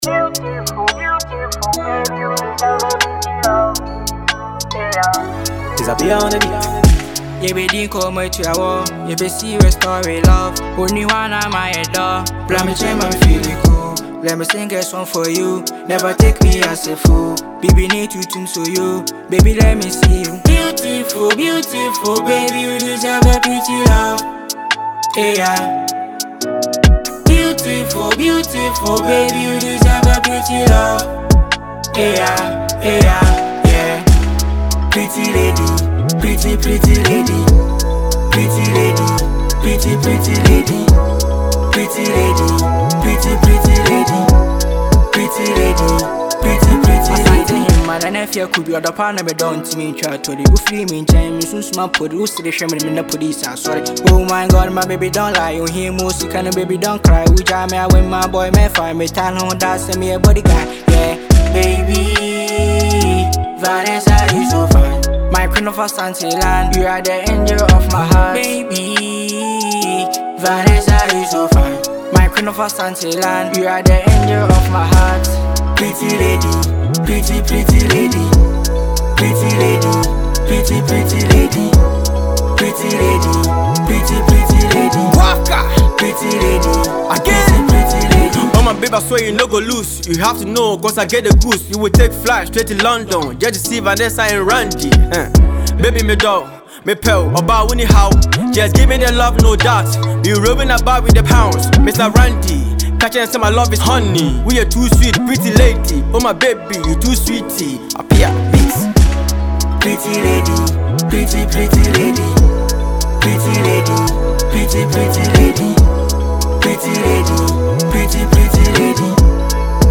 With his smooth vocals and heartfelt lyrics